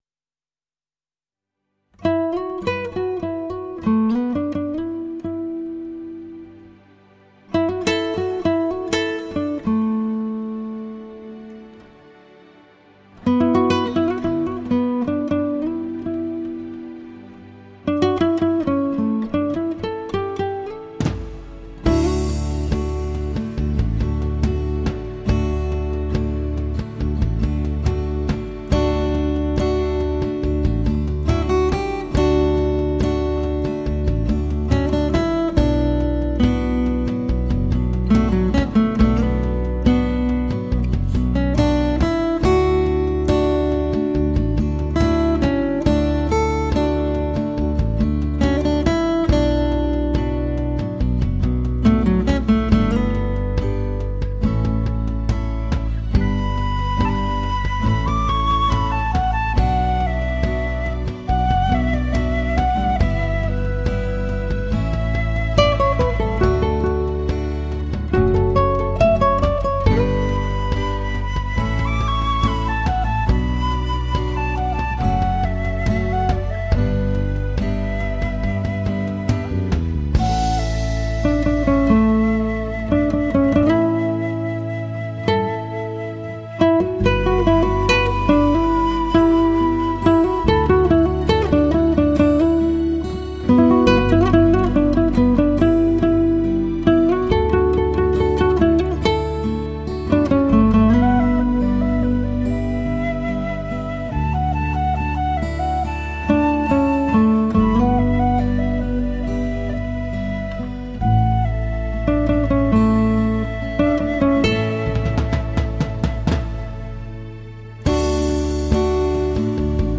极品吉他演奏 风格典雅
无可挑剔的技术 出神入化的演奏 空气感 解析力 各频段的还原度更是一流
自由 奔放 扣人心弦的吉他
演奏动态十足 旋律优美
再现高水平的临场感
华丽、亲切和谐的音乐气氛
录音出彩，配器清新，效果靓绝，音色清晰明亮，爽朗怡人，次感佳，
丰富的配器绝对发烧，令人耳目一新。